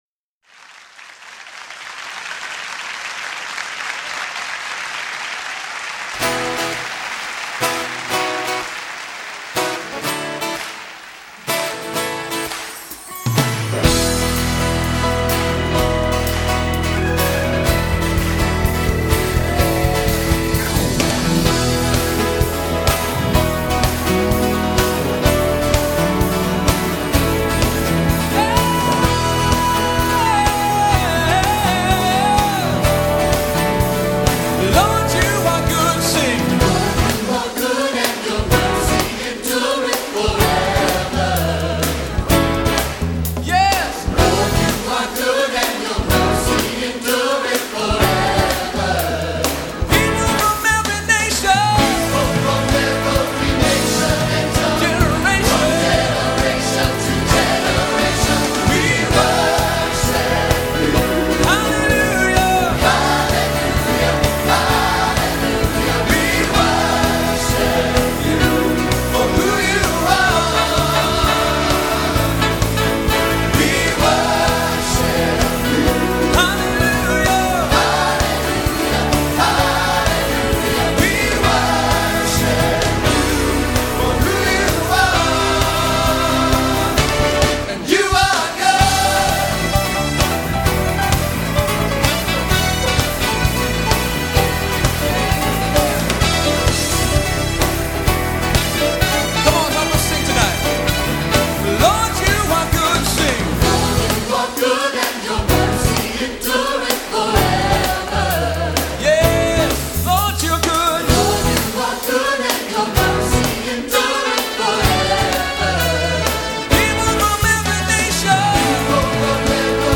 I really admire that Calvary’s team is made up of volunteers, as is ours.
Here is the audio of the Calvary team leading “You Are Good.”